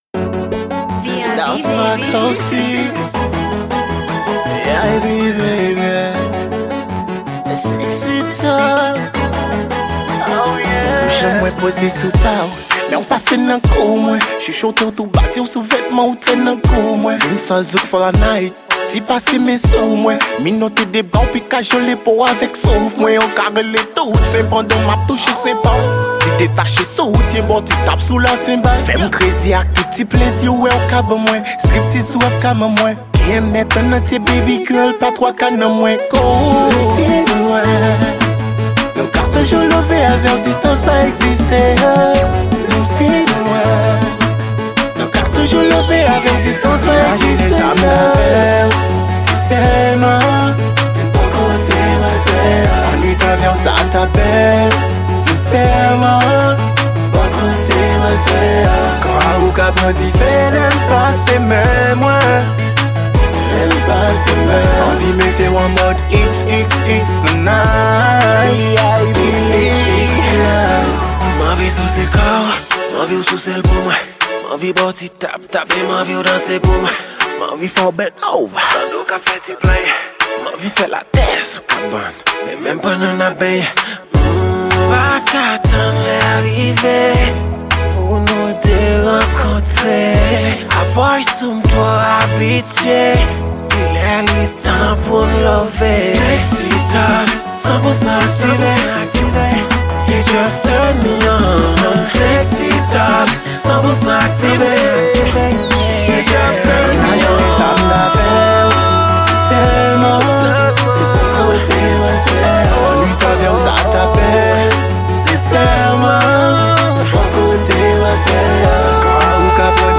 Genre: Dancehal.